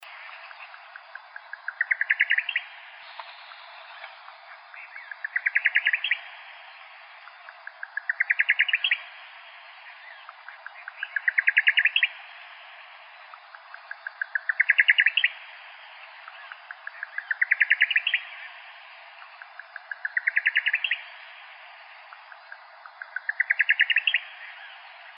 nightjar.wav